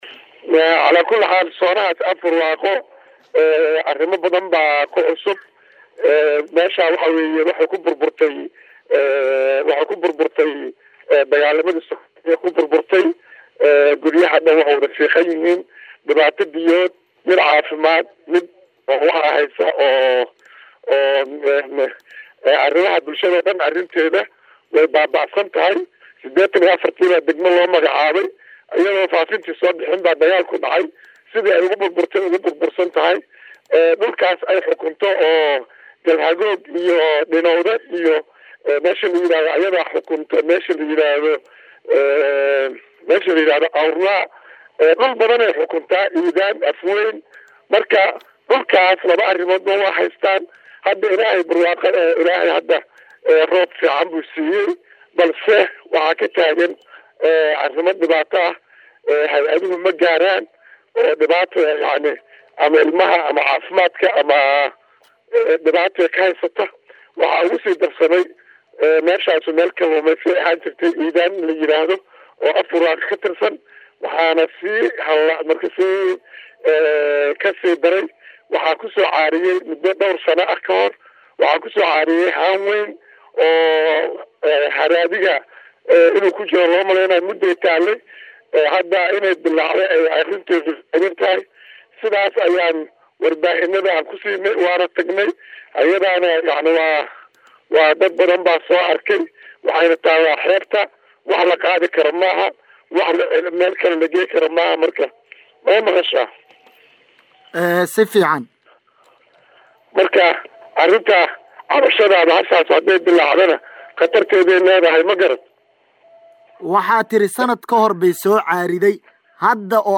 Gudoomiyaha Deegaanka Afbarwaaqo oo shaaciyey qatar ka jirto deegaankaas”Dhageyso Wareysi”
WAREYSI-GUDOOMIYAHA-DEEGAANKA-AFBARWAAQO-C.LLAHI-JAAMAC-DHOORE.mp3